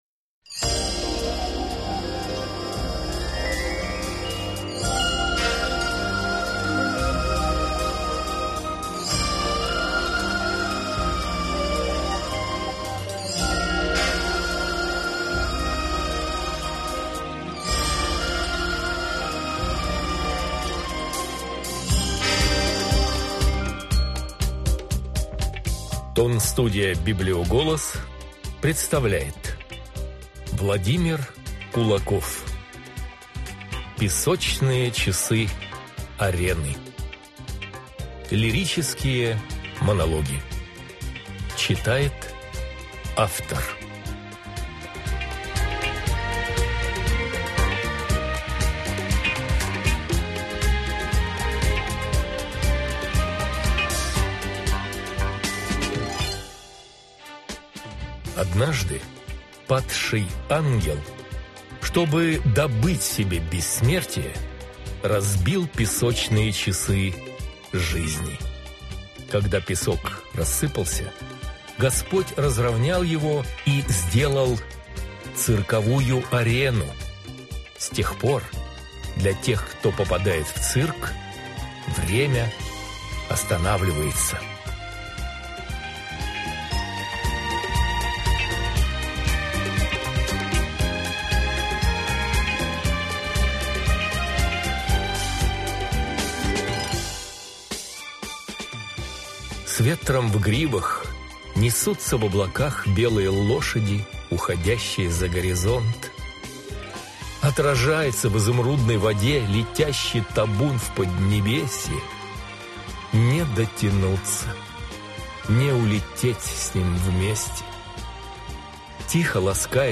Аудиокнига Sablier | Библиотека аудиокниг